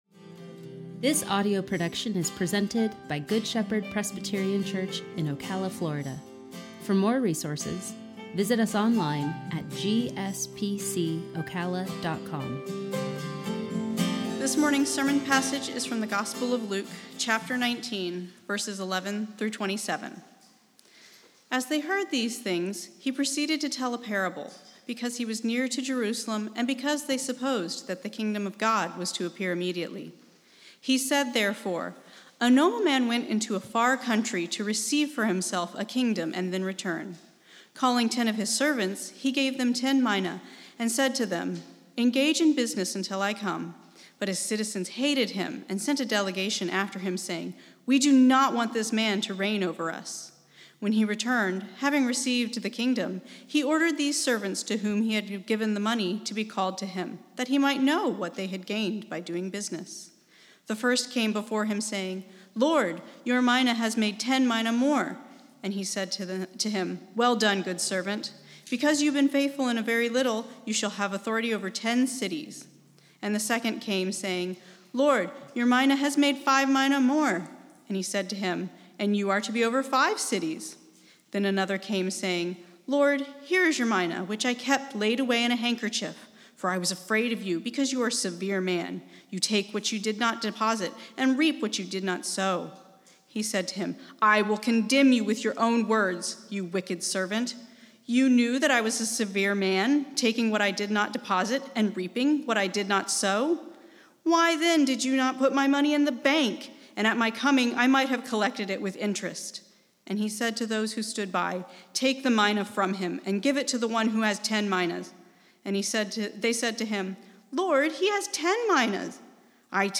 sermon-2-21-21.mp3